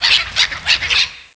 Cri de Vostourno dans Pokémon Noir et Blanc.